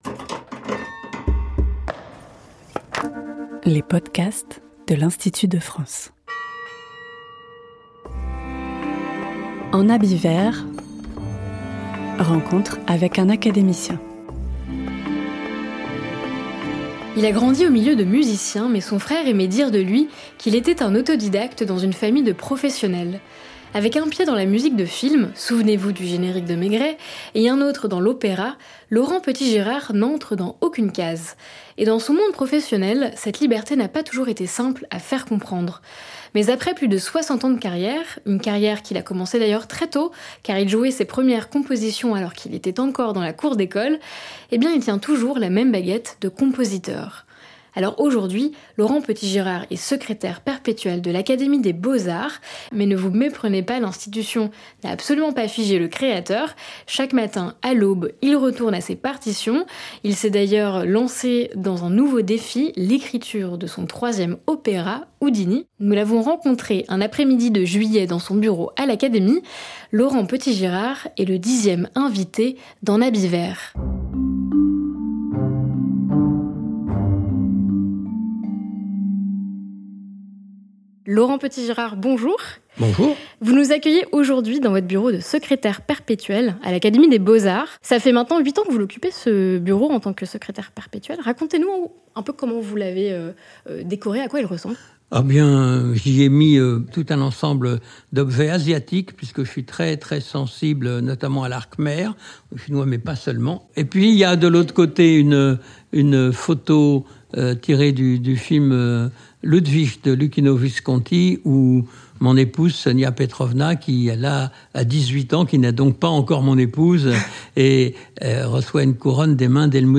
Son nouveau défi ? Un troisième opéra, consacré à l'illusionniste Houdini. C’est dans son bureau de l’Académie, par un après-midi de juillet, que nous l’avons rencontré.